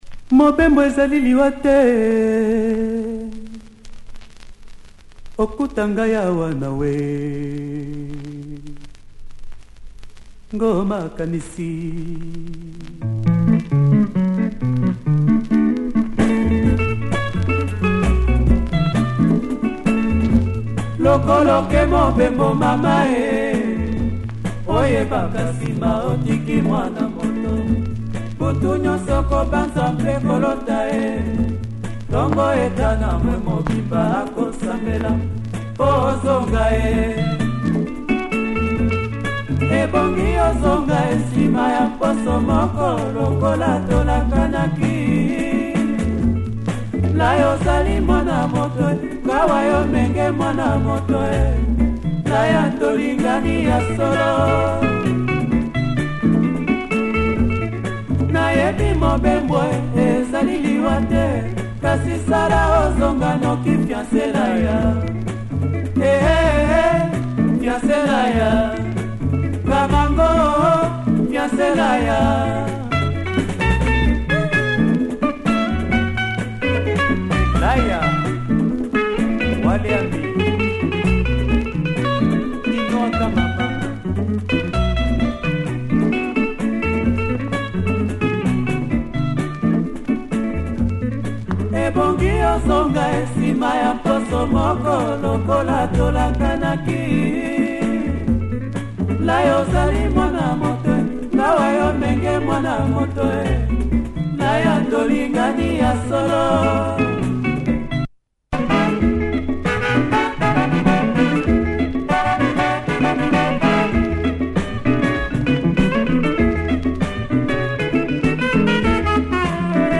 Fantastic music from Congo
check the flute breakdown